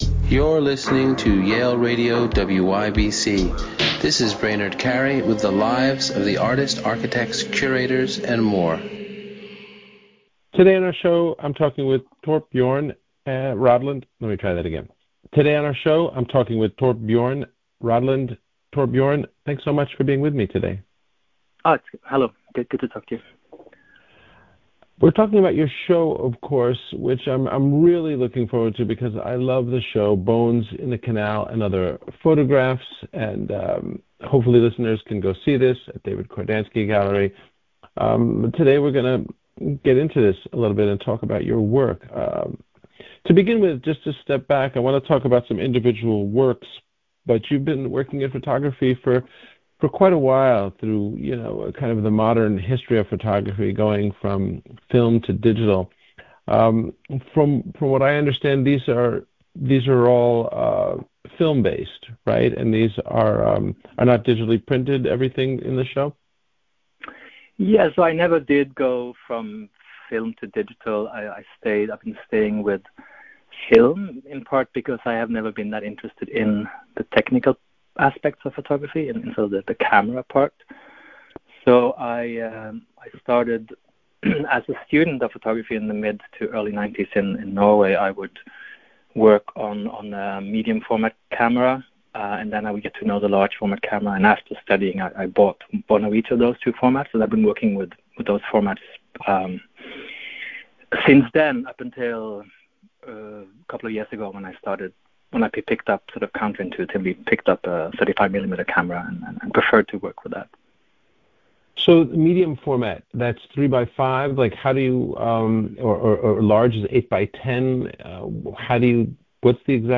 Torbjørn Rødland | Interviews from Yale University Radio WYBCX